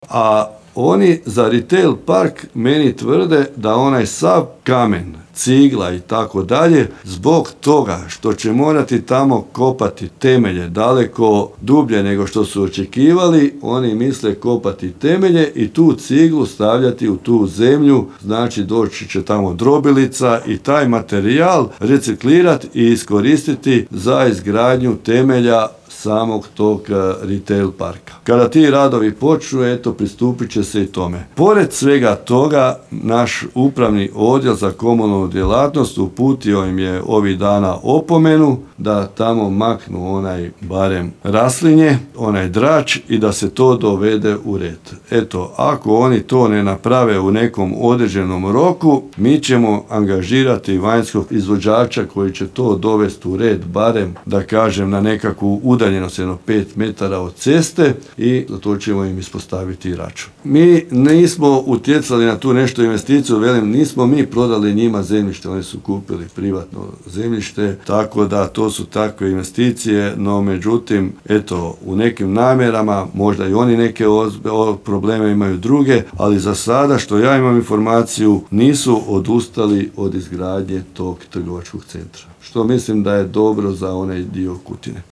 Babić zaključuje